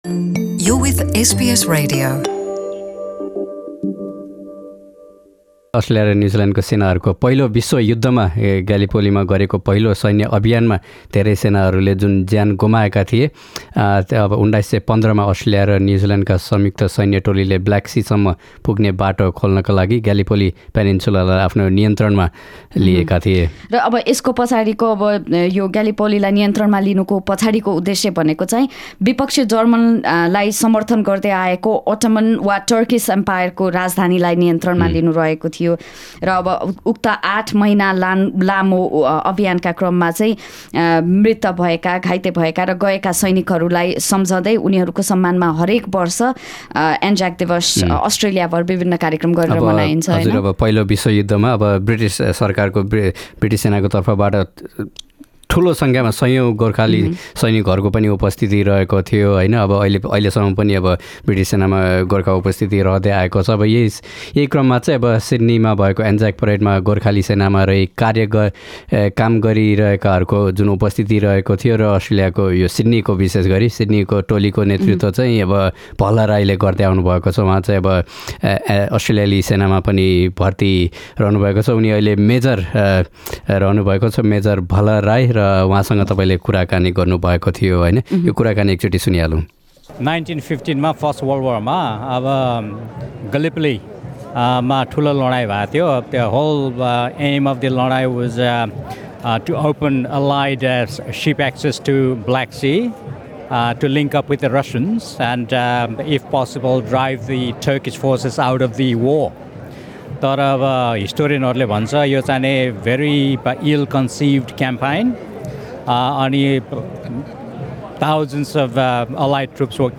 उक्त कुराकानी सुन्न माथि रहेको मिडिया प्लेयर क्लिक गर्नुहोस् एन्जाक दिवस २०१९ को सिड्नी परेडका केहि झलक: मेलबर्नमा भूपू गोर्खा सैनिक र उनीहरुका परिवार सहभागी परेडको भिडियो सिड्नी परेडका भिडियोहरु Share